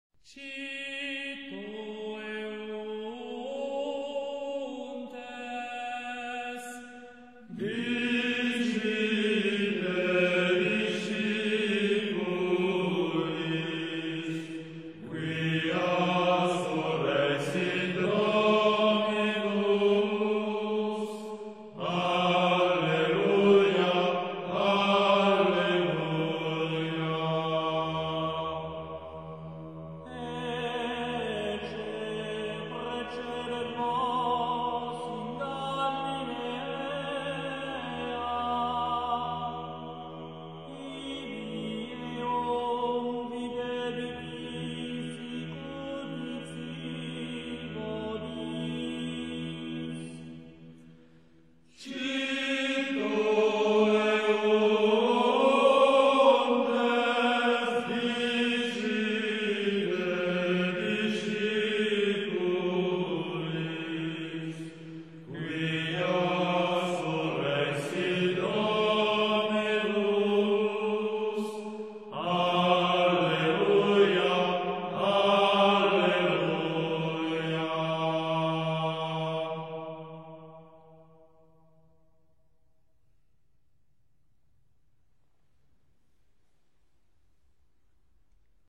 Староримские хоралы в исполнении ансамбля Органум и Л.Ангелопулосом. Запись 1998 г.